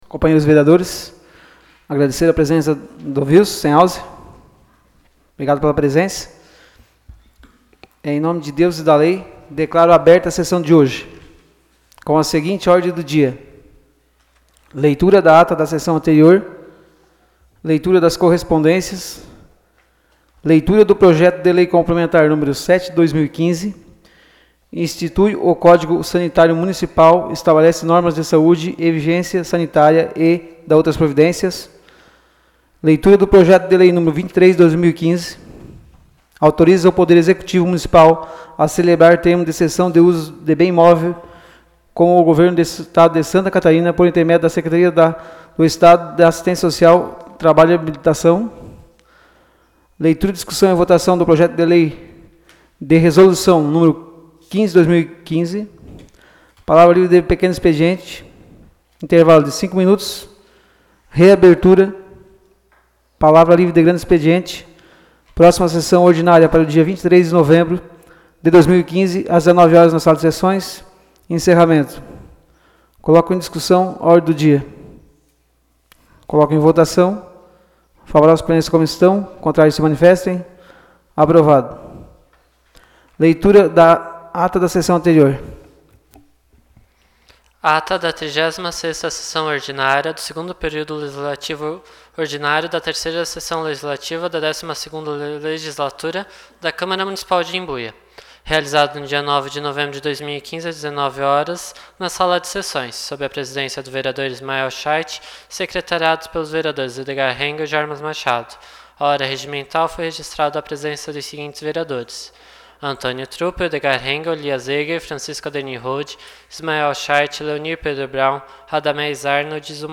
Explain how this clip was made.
Áudio da Sessão Ordinária de 16 de novembro de 2015.